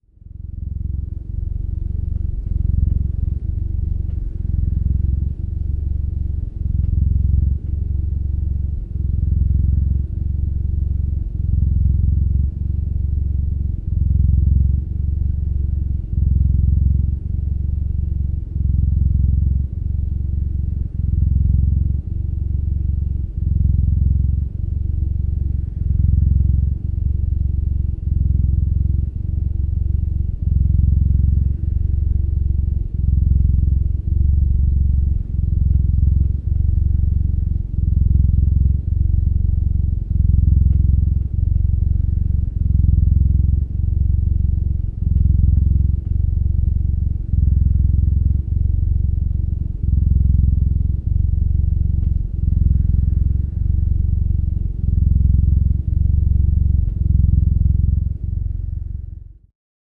Descarga de Sonidos mp3 Gratis: gato ronroneo 1.
descargar sonido mp3 gato ronroneo 1